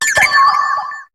Cri de Phione dans Pokémon HOME.